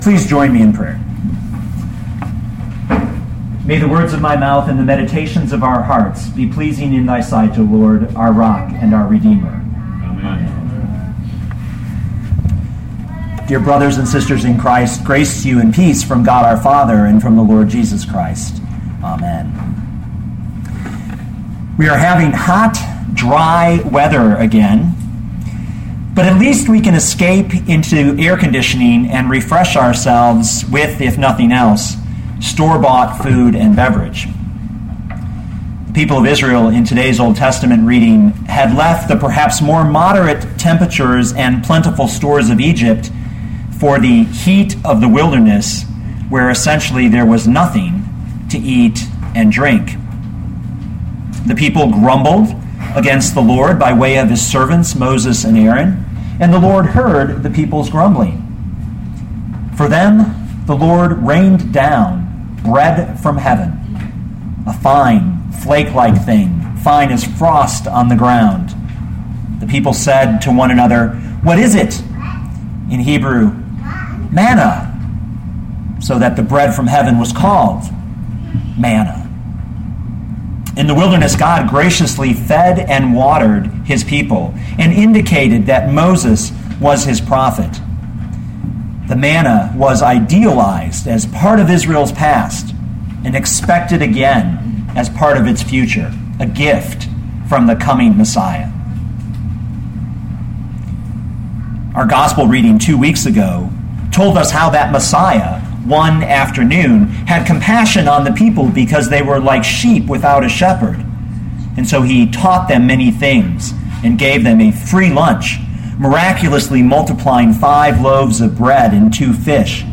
2012 John 6:22-35 Listen to the sermon with the player below, or, download the audio.